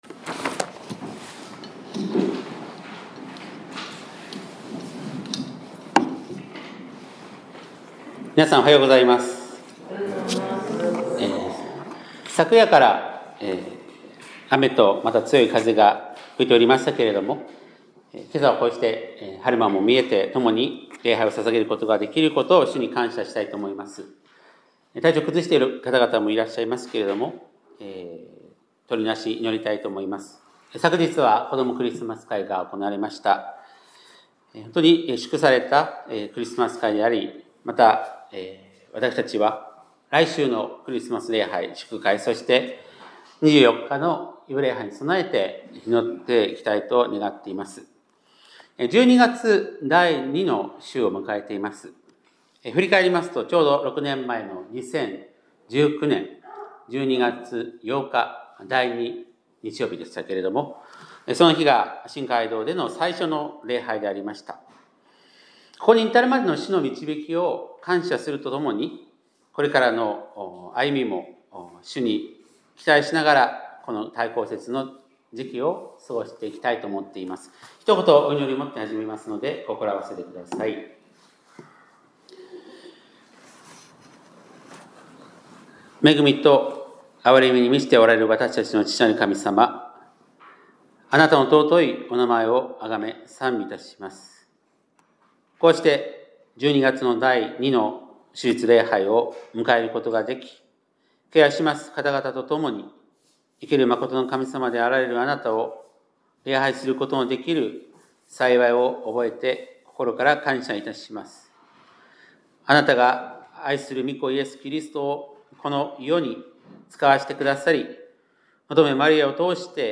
2025年12月14日（日）礼拝メッセージ